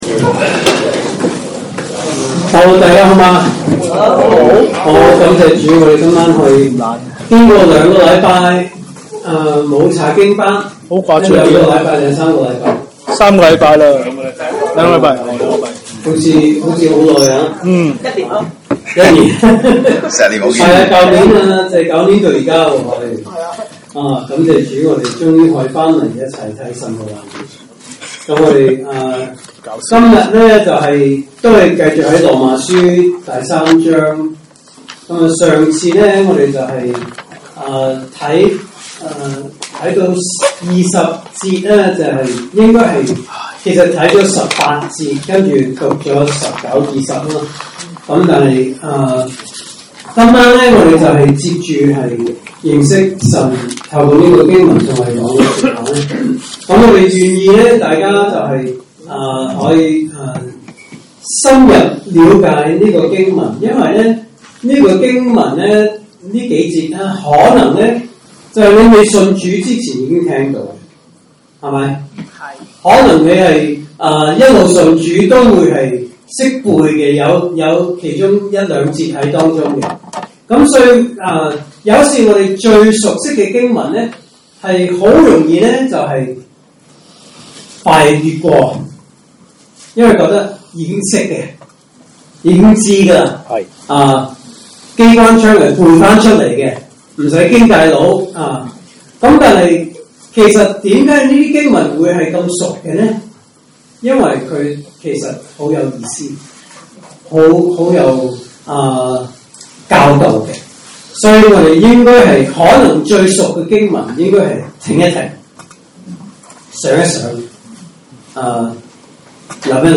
證道信息: “羅馬書 3:19-26